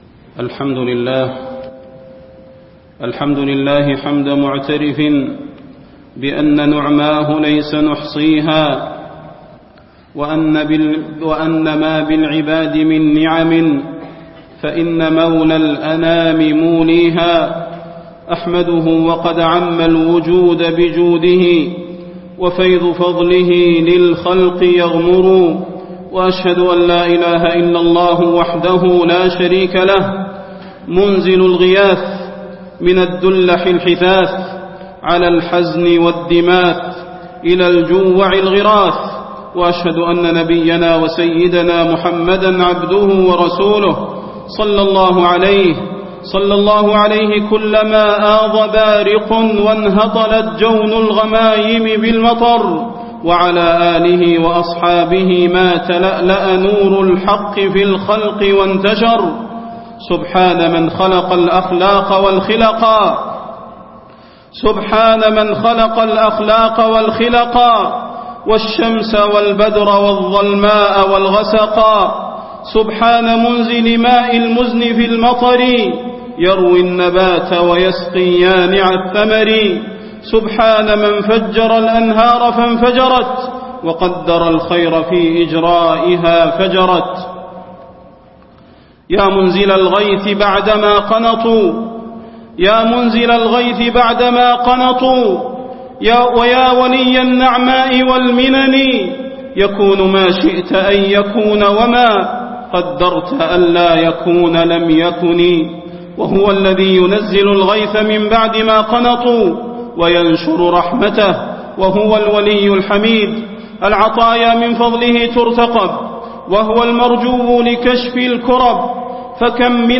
خطبة الاستسقاء - المدينة- الشيخ صلاح البدير
تاريخ النشر ١ صفر ١٤٣٣ هـ المكان: المسجد النبوي الشيخ: فضيلة الشيخ د. صلاح بن محمد البدير فضيلة الشيخ د. صلاح بن محمد البدير خطبة الاستسقاء - المدينة- الشيخ صلاح البدير